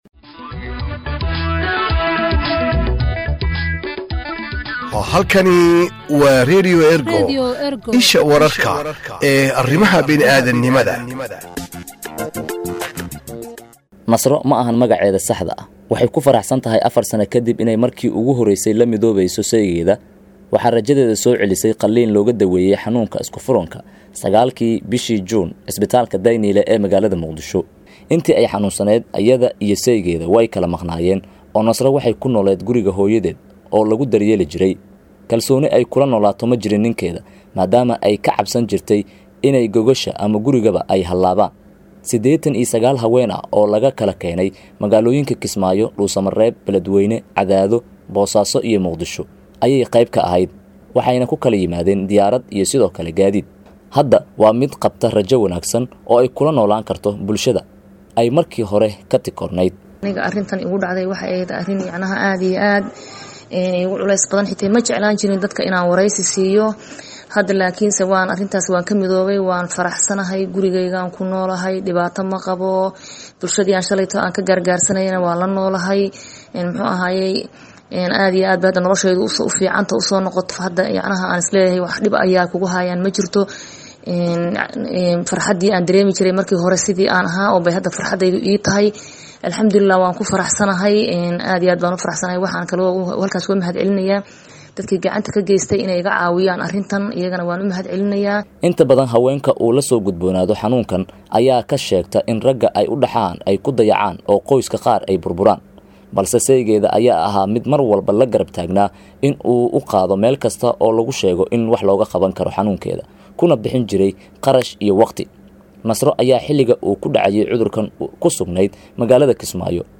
Warbixin-Isku-furanka-Isbitaalka-Dayniile-.mp3